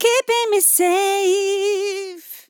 Keeping me safe Vocal Sample
DISCO VIBES | dry | english | female
Categories: Vocals
POLI-LYRICS-Fills-120bpm-Fm-15.wav